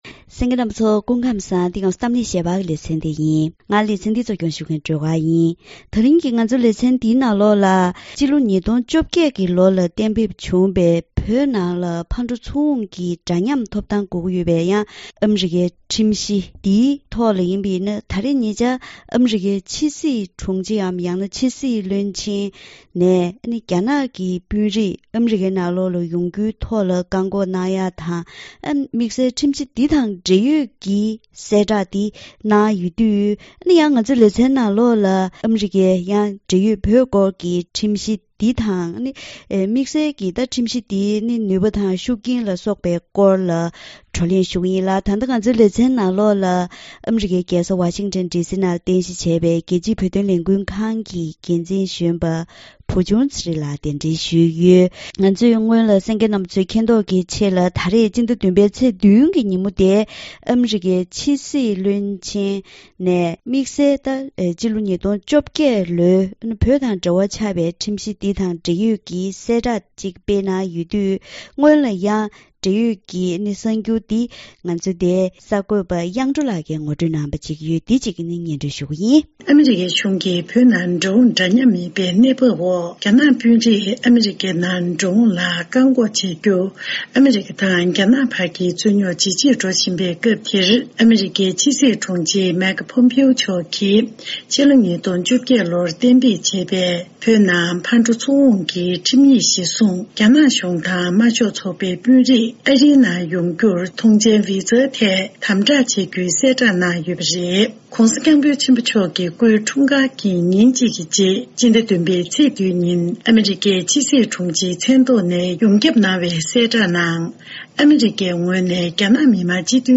དེ་རིང་གི་གཏམ་གླེང་ཞལ་པར་ལེ་ཚན་ནང་སྤྱི་ལོ་༢༠༡༨ལོར་གཏན་འབེབས་གནང་པའི་བོད་ནང་ཕར་འགྲོ་ཚུར་འོང་གི་འདྲ་མཉམ་ཐོབ་ཐང་སྐོར་གྱི་ཁྲིམས་གཞི་འདིའི་སྐོར་ལ་ཨ་རིའི་ཕྱི་སྲིད་དྲུང་ཆེའམ་ཕྱི་སྲིད་བློན་ཆེན་ནས་གསལ་བསྒྲགས་ཤིག་བཏོན་པ་དེའི་ཐོག་ནས་ཁྲིམས་གཞི་འདིའི་ནུས་པ་དང་། ཤུགས་རྐྱེན་དང་། དེ་བཞིན་ཨ་རིས་བོད་ཐོག་འཛིན་པའི་སྲིད་ཇུས་སོགས་ཀྱི་སྐོར་ལ་བཀའ་མོལ་ཞུས་པ་ཞིག་གསན་རོགས་གནང་།